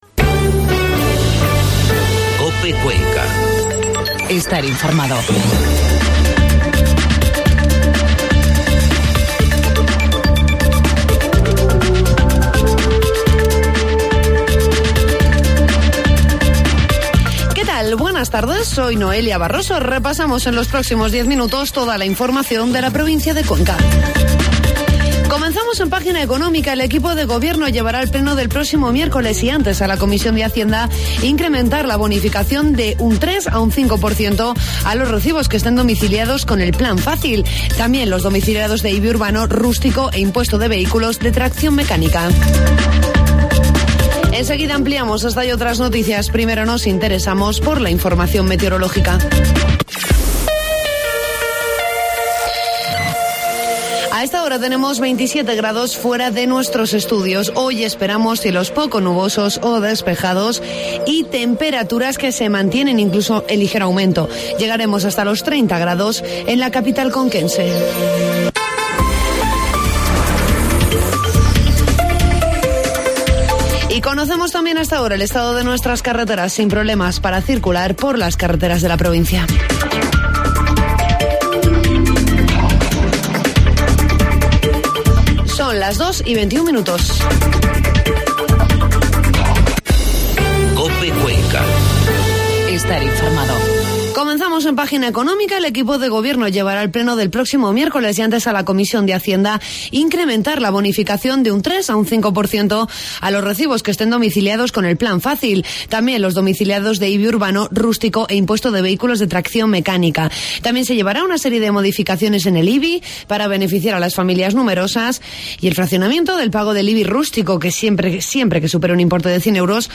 Informativo mediodía COPE Cuenca 5 de octubre